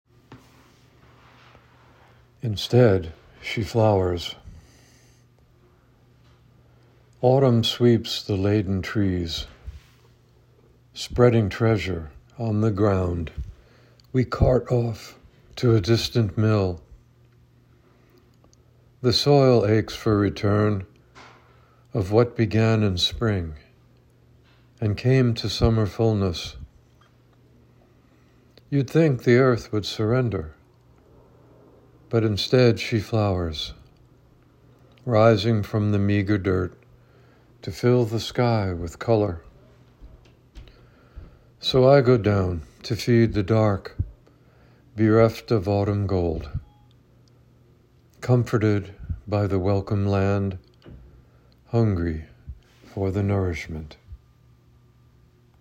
Reading of “Instead She Flowers”